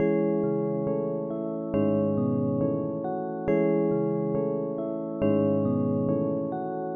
Witcha_Main Bell.wav